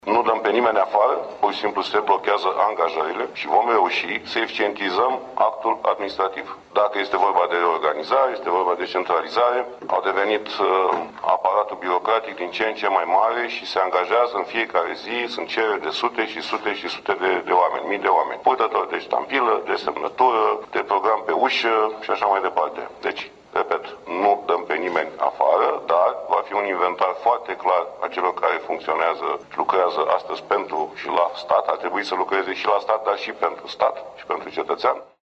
Premierul Mihai Tudose a afirmat, joi, la începutul şedinţei Executivului, pe tema Ordonanţei privind suspendarea ocupării posturilor, că nu va fi nimeni dat afară din administraţia centrală, ci posturile vor fi blocate pentru ”eficientizarea actului administrativ”.